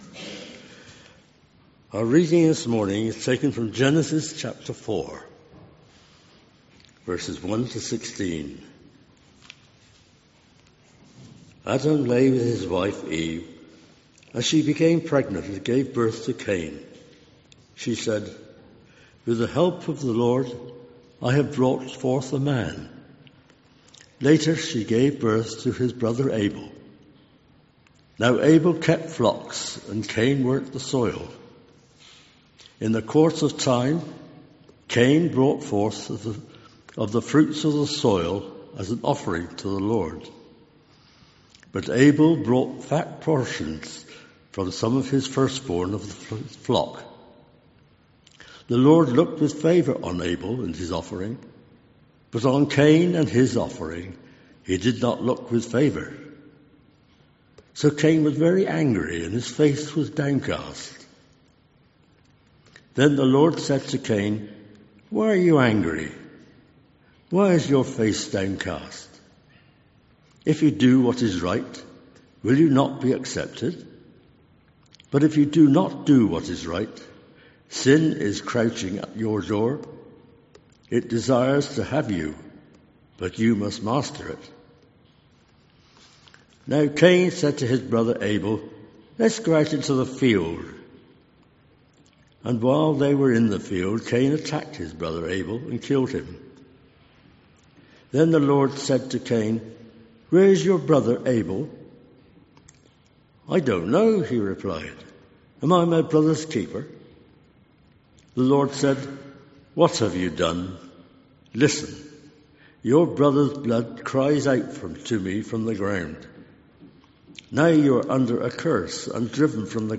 An audio file of the service is available to listen to.